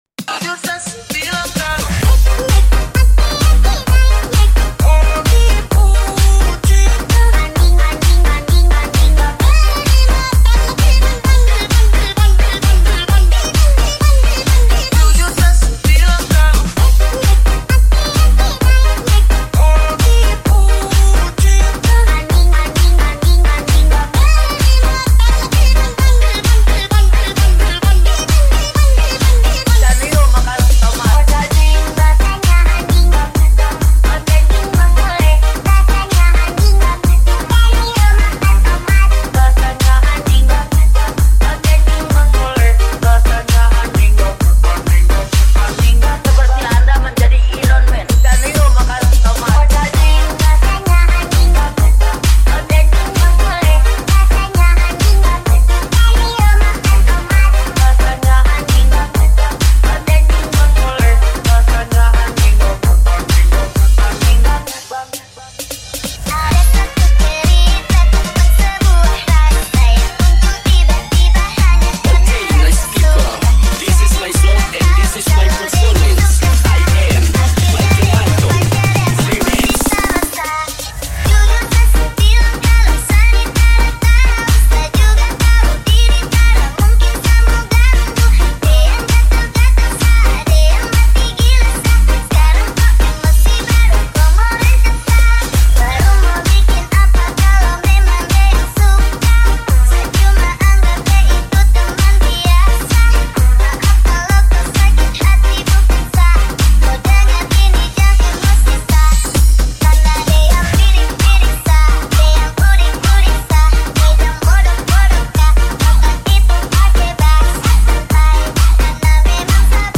High quality Sri Lankan remix MP3 (3.5).